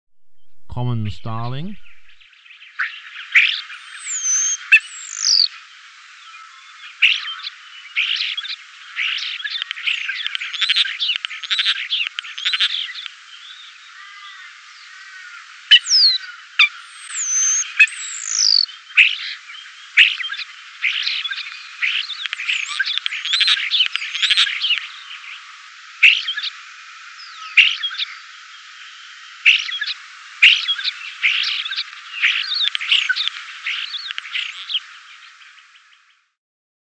Starlings
clicks, hissing and whistling which can hardly be called song. But, it also has an amazing mimicry ability, whether it be other bird calls, songs, or even words (which is it's function in Henry IV).
74 Common Starling.mp3